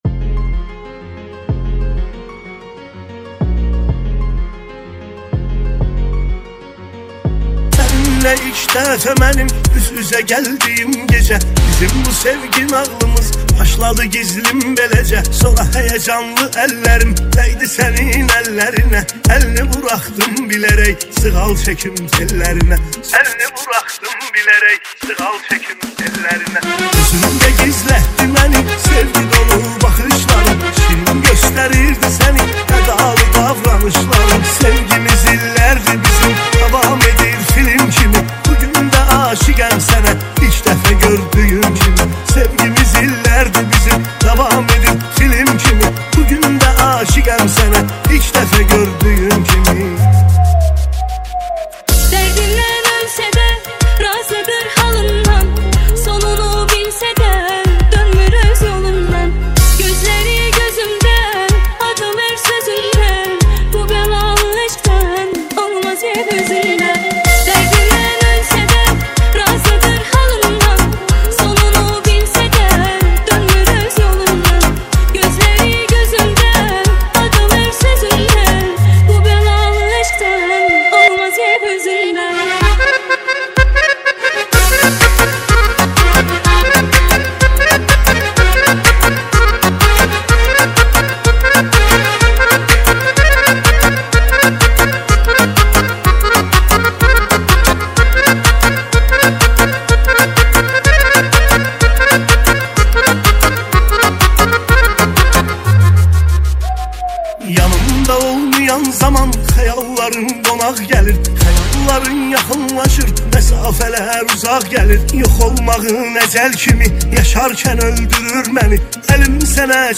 اگر به دنبال قطعه‌ای عمیق، آرام و عاشقانه هستید